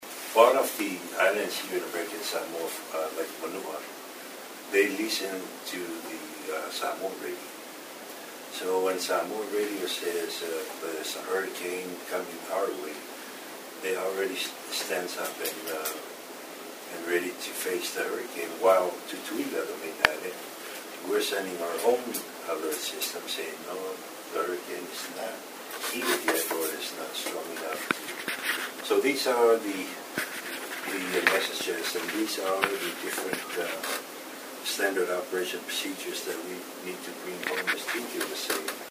Collaboration was also stressed by Lt Governor Lemanu Peleti Mauga when he spoke about the importance of weather forecasters from Samoa and American Samoa understanding each other’s weather systems.